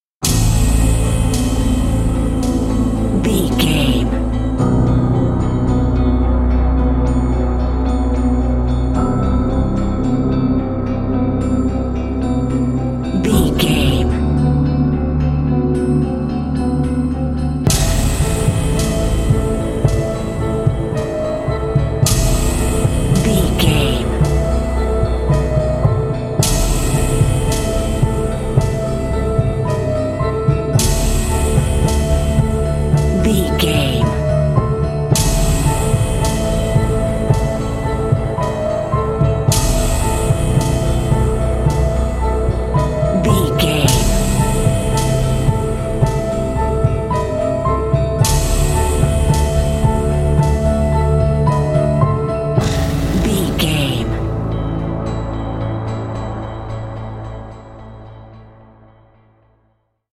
Thriller
Aeolian/Minor
Slow
synthesiser
electric piano
percussion